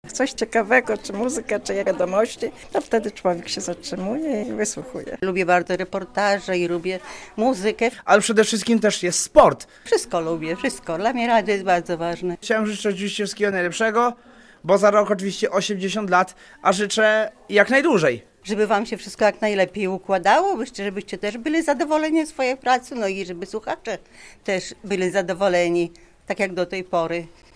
Dzień otwarty - relacja